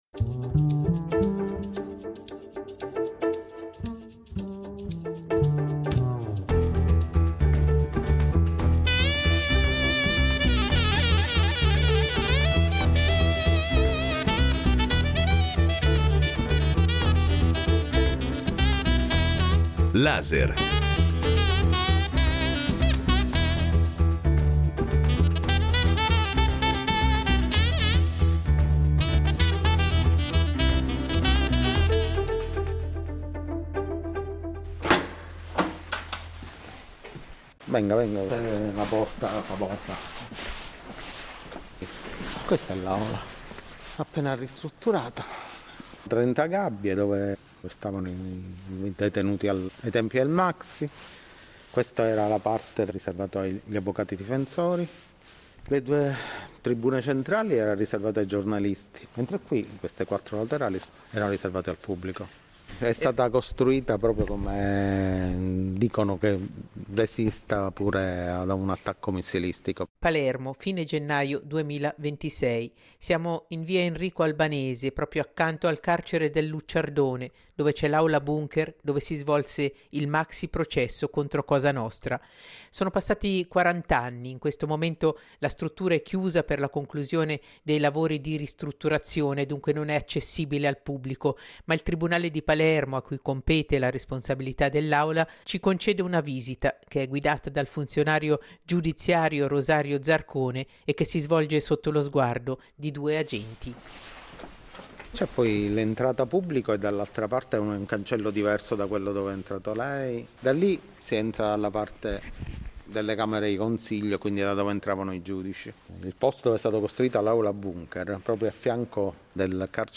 Reportage da Palermo a quarant’anni dal maxiprocesso contro Cosa Nostra